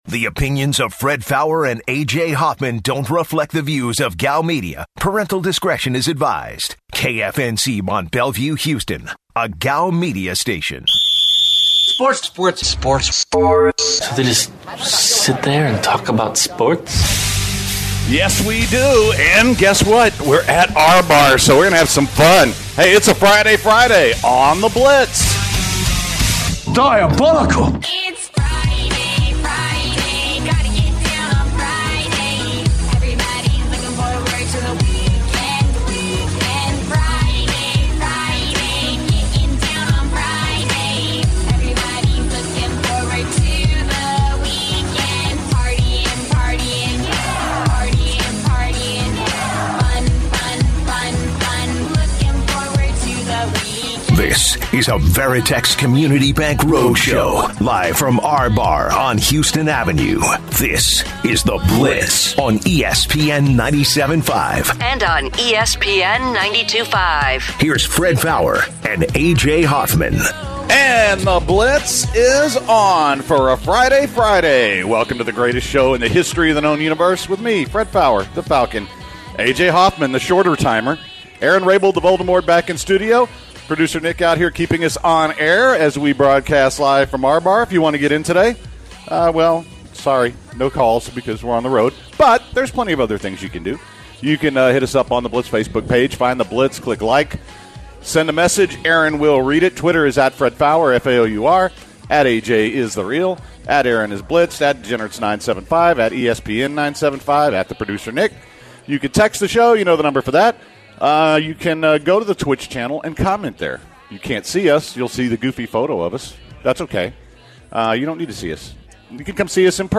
live from the R Bar Houston